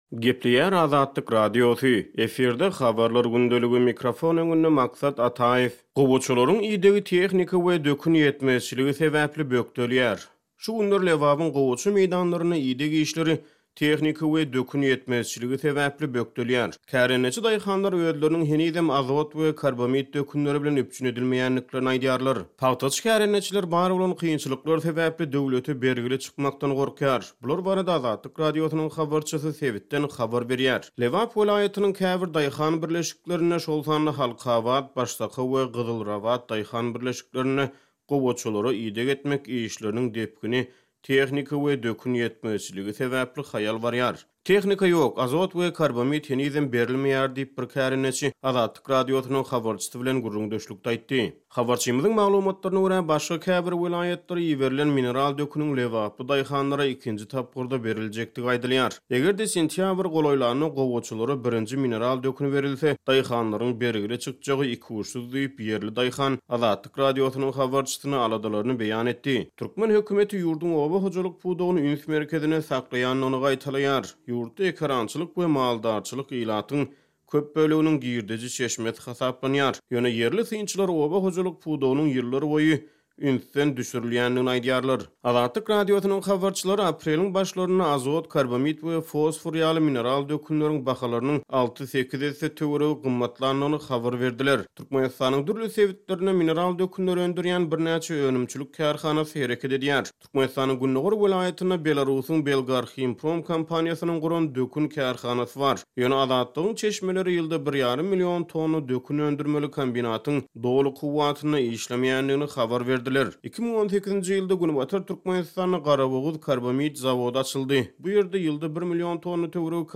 Bular barada Azatlyk Radiosynyň habarçysy sebitden habar berýär.